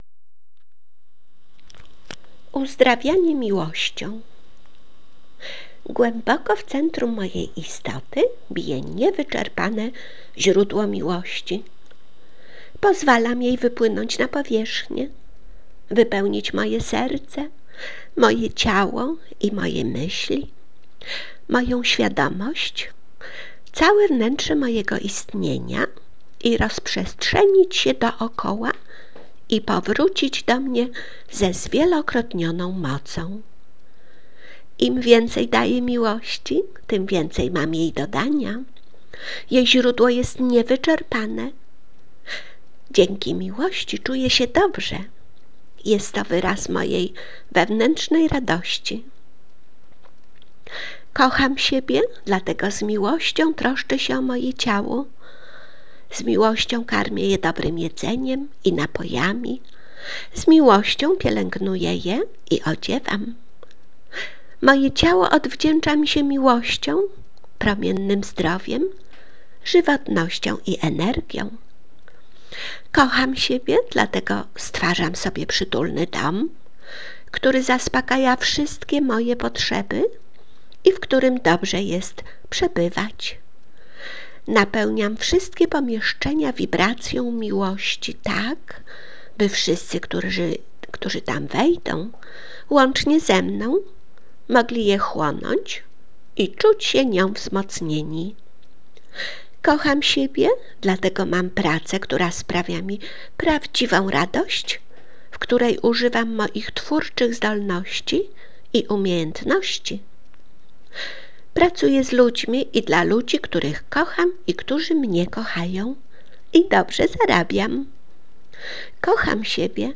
medytacja Louise L. Hay „Uzdrawianie miłością”